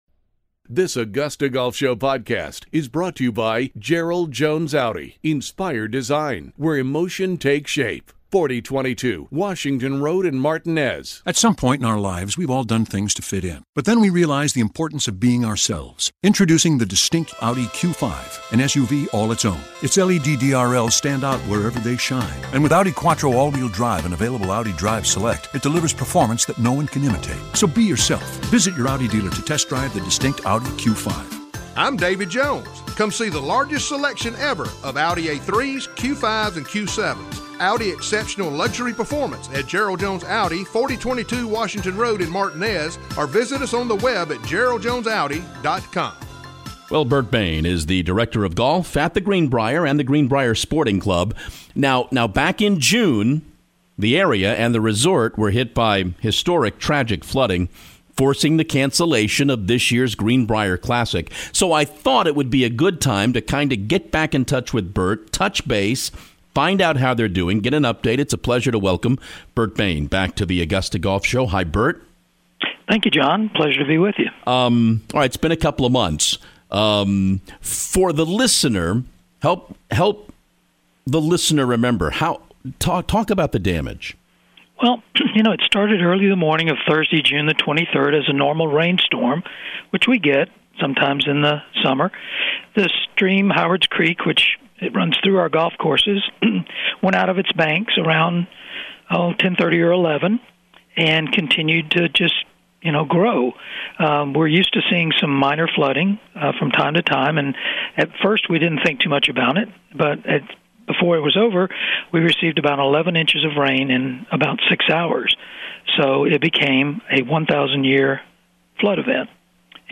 The AGS Interview